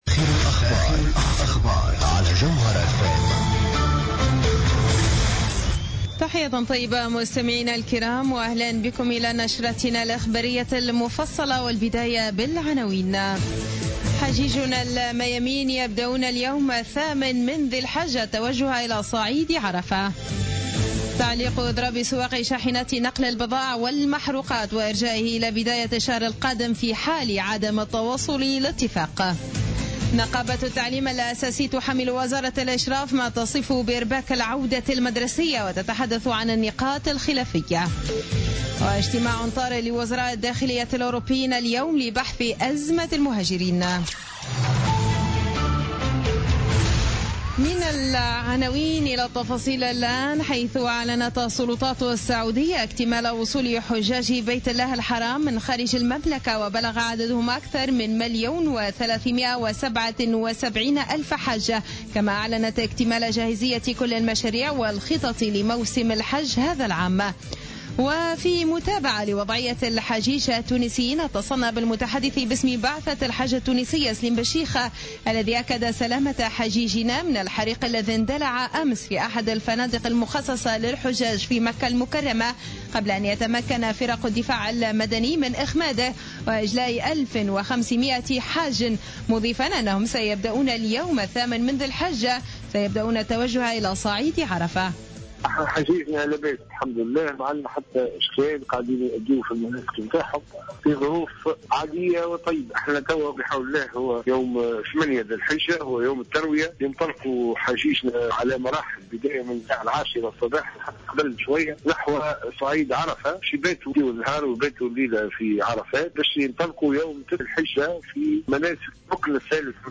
نشرة أخبار منتصف الليل ليوم الثلاثاء 22 سبتمبر 2015